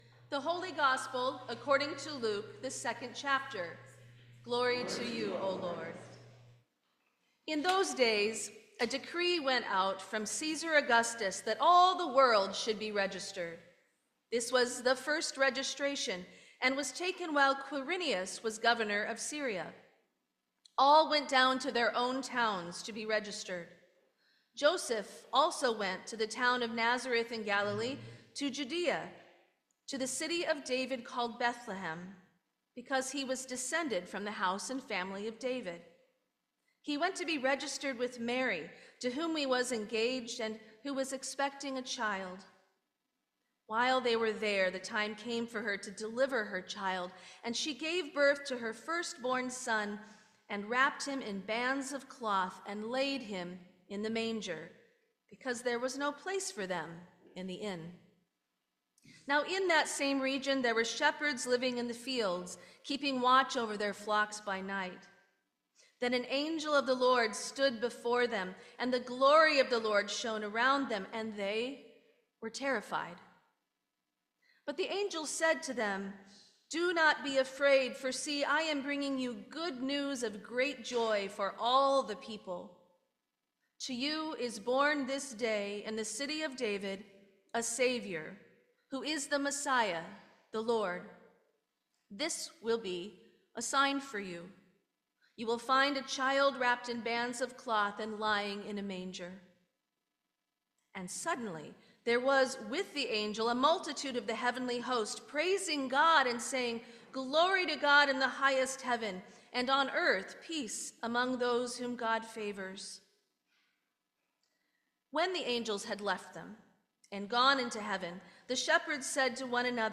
Sermon for Christmas Eve 2024